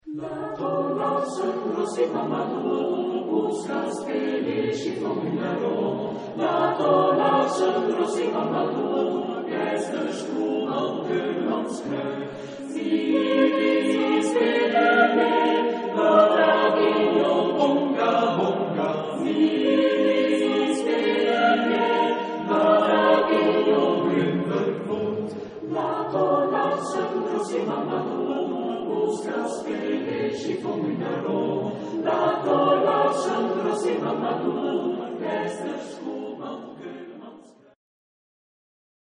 Genre-Style-Forme : Chanson ; Profane
Type de choeur : SATB  (4 voix mixtes )
Tonalité : ré majeur